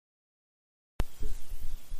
Free Foley sound effect: Stapler.
Stapler
298_stapler.mp3